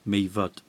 Welsh pronunciation), is a small village, community and electoral ward seven miles (11 km) north-west of Welshpool in Montgomeryshire, Powys, Wales, on the A495 road and located in the valley of the River Vyrnwy.